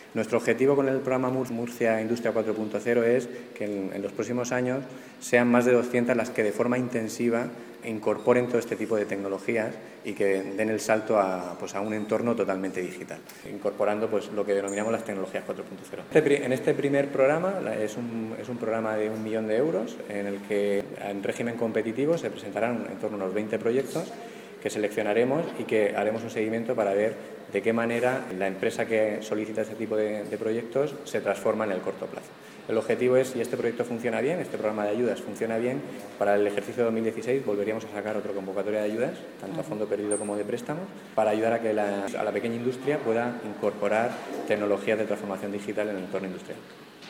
Declaraciones del director del Instituto de Fomento, Javier Celdrán – ‘Jornada Murcia Industria 4.0’. I